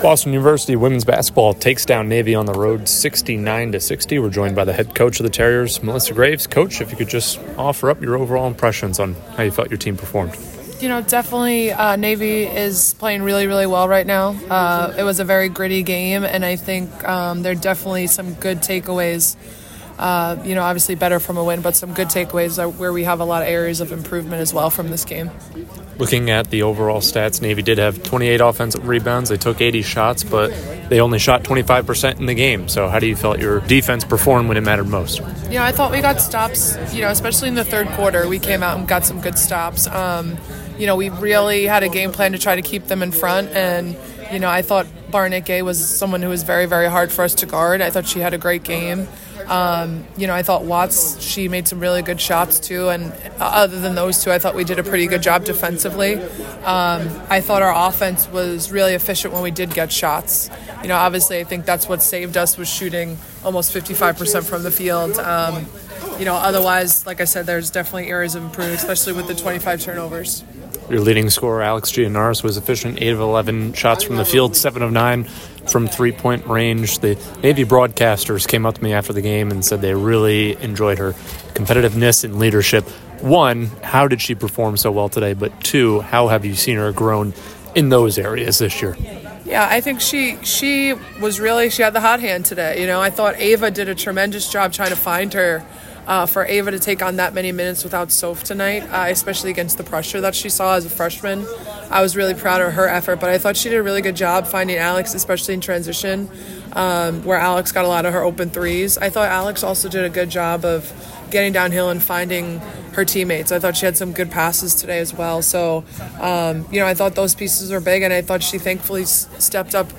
WBB_Navy_2_Postgame.mp3